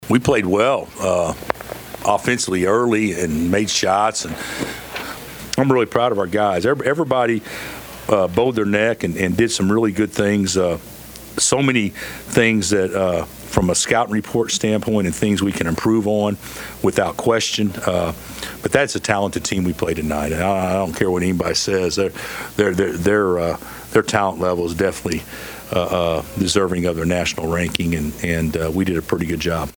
Kansas Coach Bill Self called it a good win.